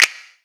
DDW Snap.wav